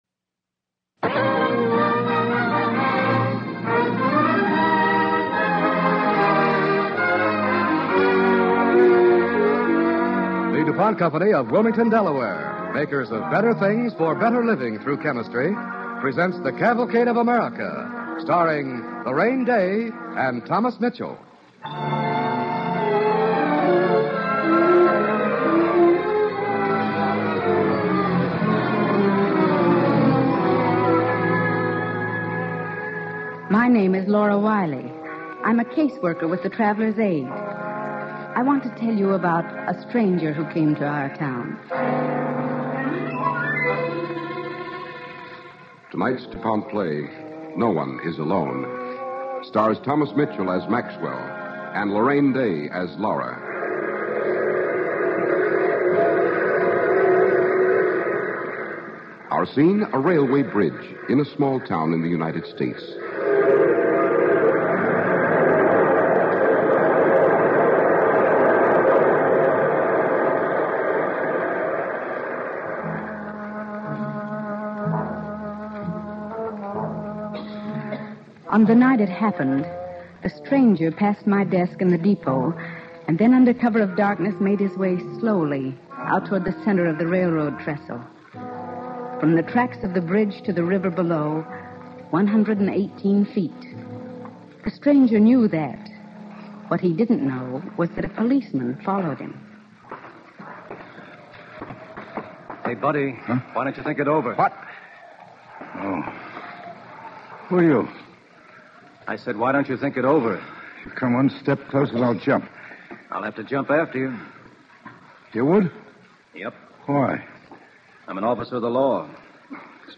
starring Larraine Day and Thomas Mitchell
Cavalcade of America Radio Program